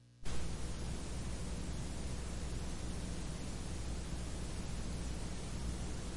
盒式磁带 " 盒式磁带的嘶嘶声 噗通声
描述：盒式磁带嘶嘶声on.flac
Tag: 嘶嘶声 盒式磁带 磁带